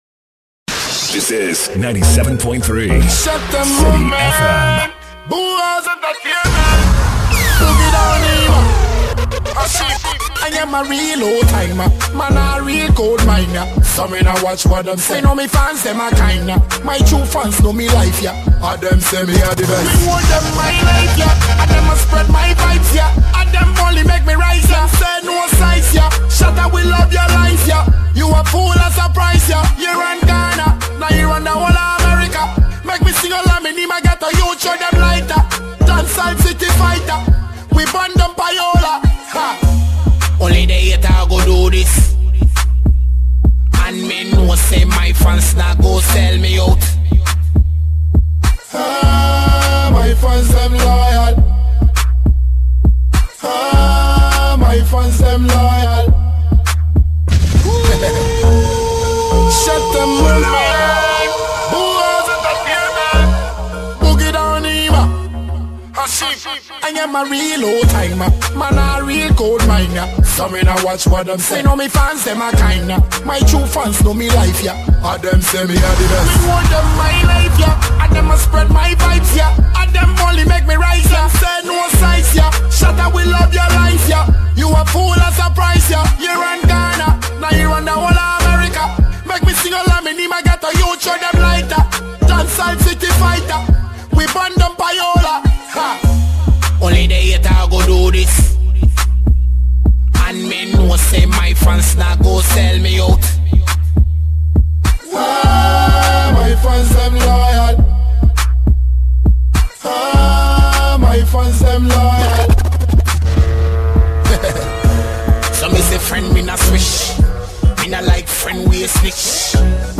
Genre: Mix/Mixtape
pure Dancehall music